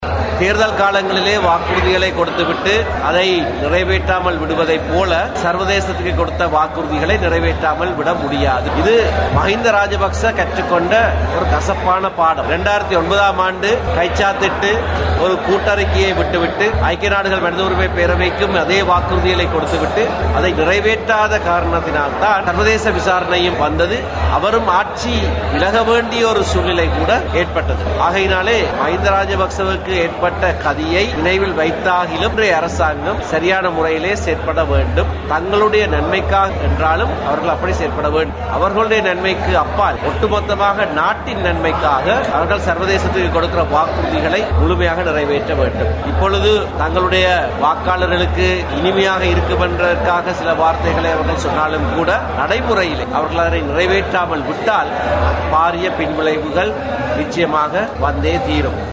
தமிழ் தேசிய கூட்டமைப்பின் ஊடக பேச்சாளர் எம் ஏ சுமந்திரன் இதனை குறிப்பிட்டார்.
யாழ்ப்பாணத்தில் இடம்பெற்ற நிகழ்வொன்றினையடுத்து, ஊடகங்களுக்கு கருத்து தெரிவிக்கும் போதே அவர் இதனை தெரிவித்தார்.